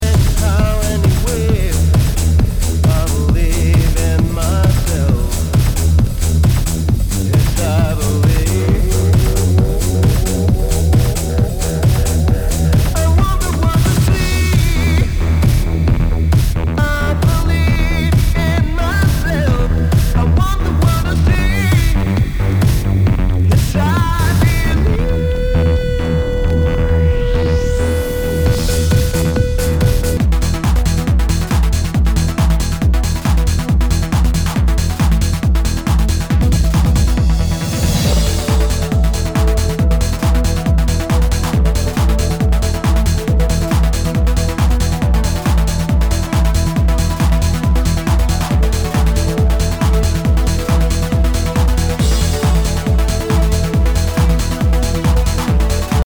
HOUSE/TECHNO/ELECTRO
ナイス！ヴォーカル・トランス！！